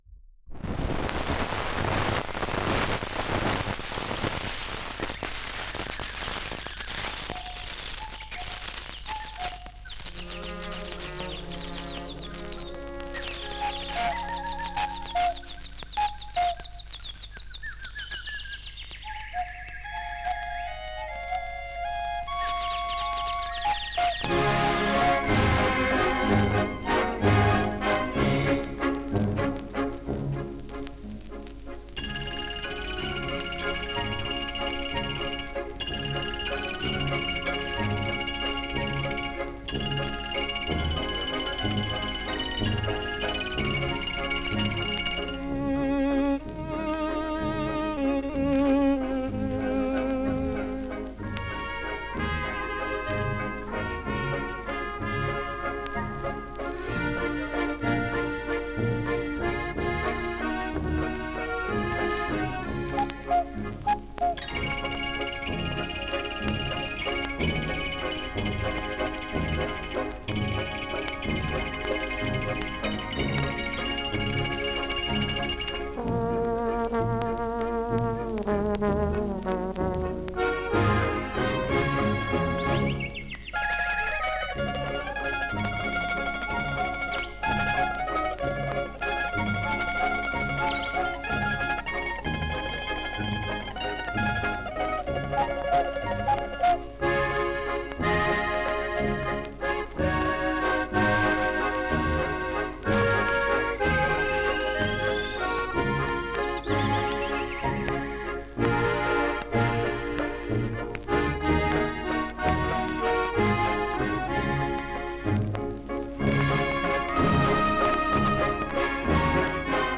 Другой оркестр и другое название.
=== Columbia Salon Orchestra - Waldeslust - Walzer, Columbia (DW 2541)